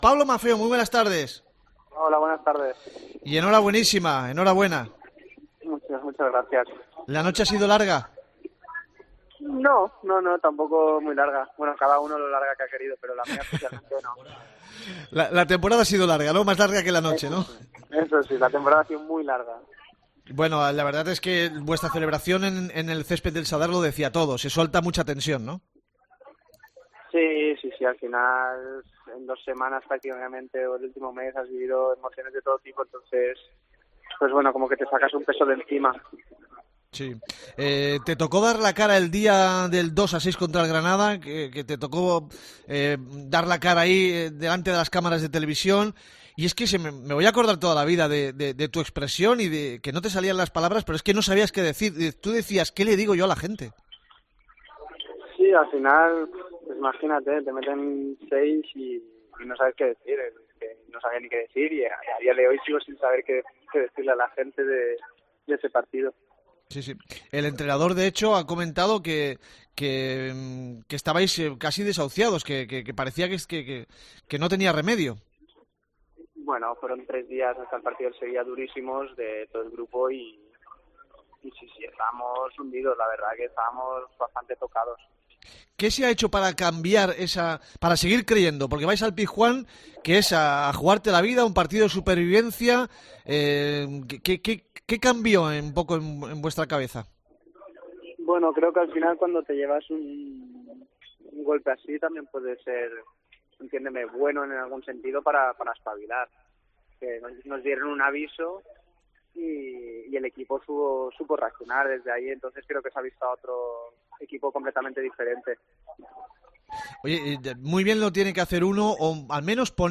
Horas después de conseguida la permanencia hablamos con Pablo Maffeo, uno de los jugadores más valorados por la afición, sobre la jornada final, cómo lo vivieron, cómo han sido estas últimas jornadas y sobre el futuro.